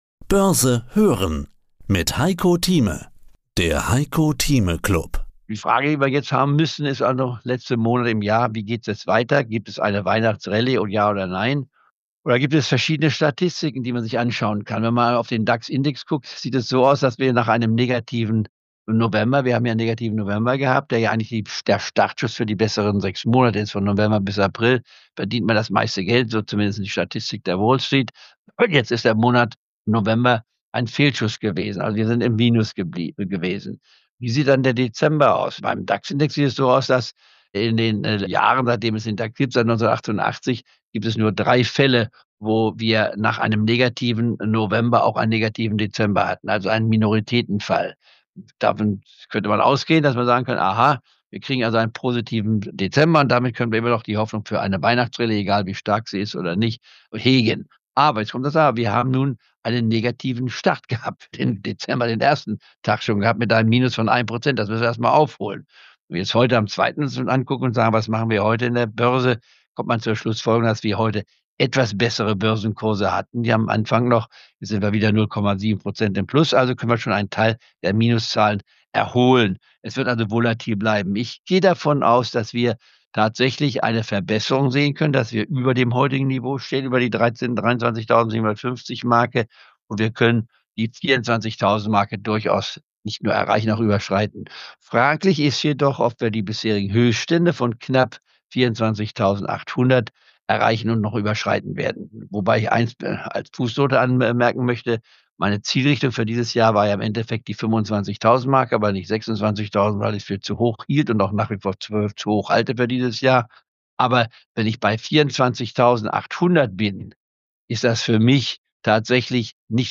Ein Clubtalk mit Tempo, Haltung und klaren Marken für den Jahresendspurt.